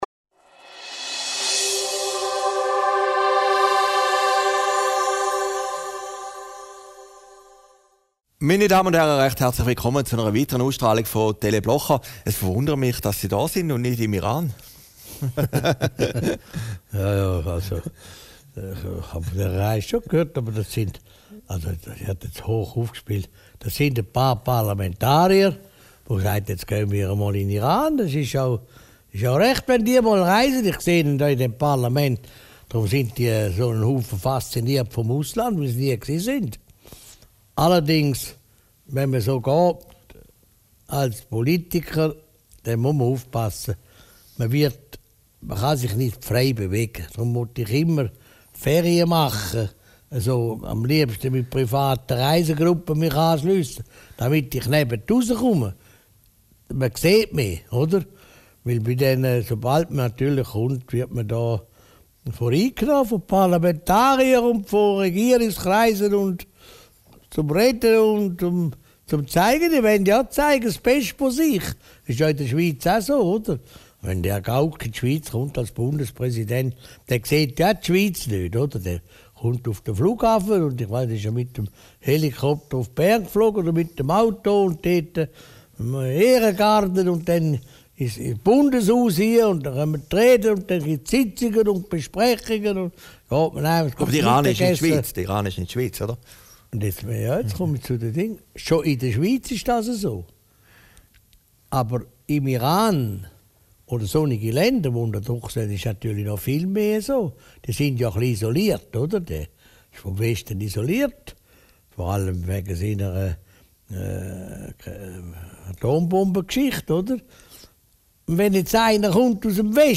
Aufgezeichnet in Herrliberg, 25. April 2014